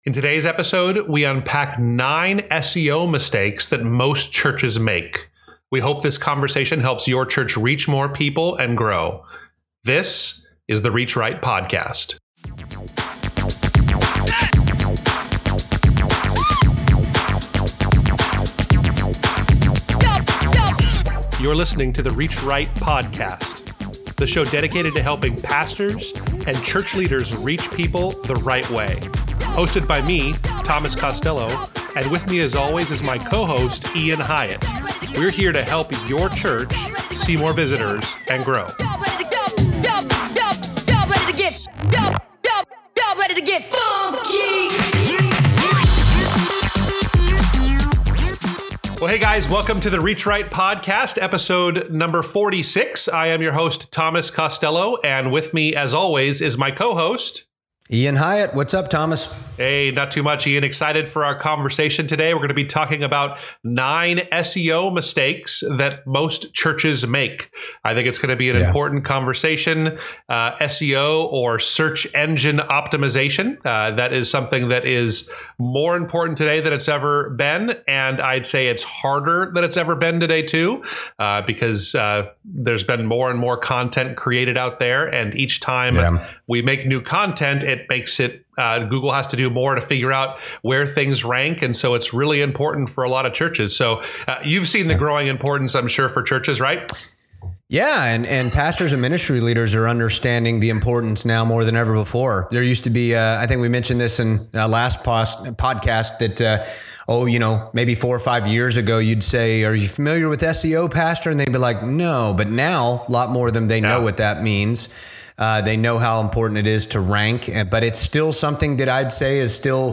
We hope this conversation helps your church reach more people and grow.